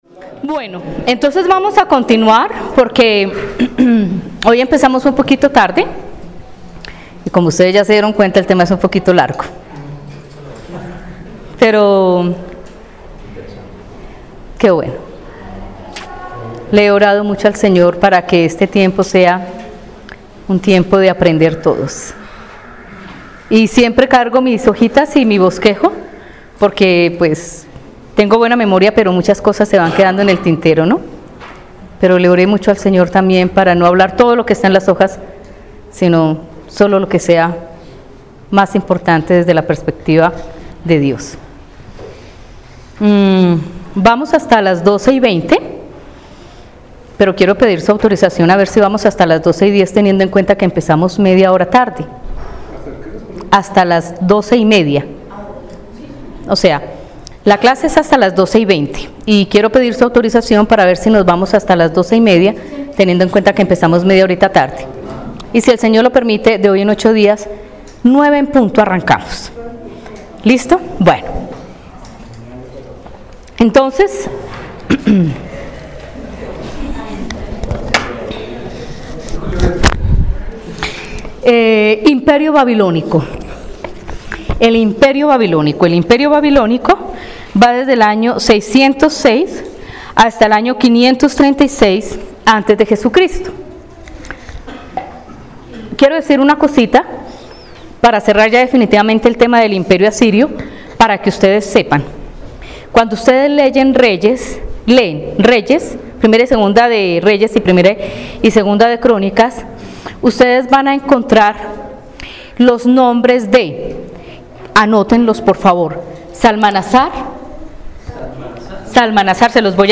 Lección 1: Introducción a la historia del cristianismo II (Marzo 3, 2018)